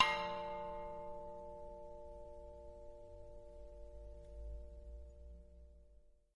描述：在楼梯间撞击栏杆的钟声
Tag: 敲击 编钟